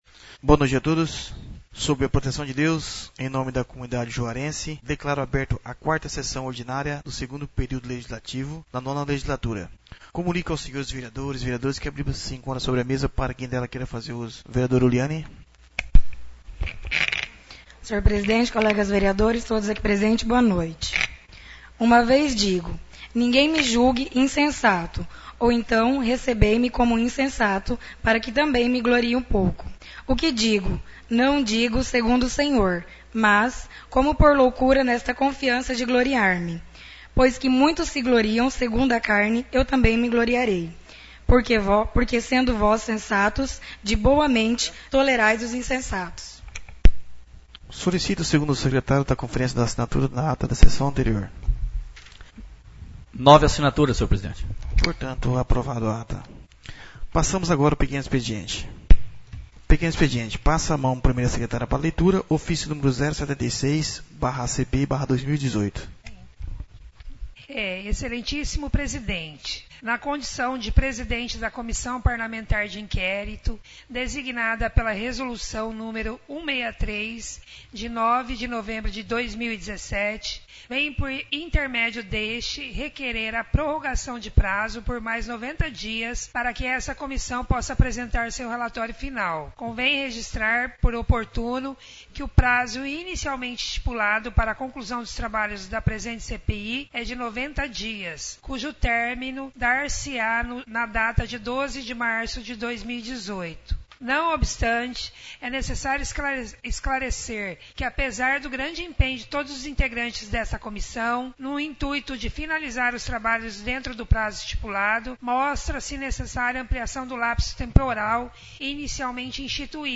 Áudio das Sessões da Câmara Municípal de Juara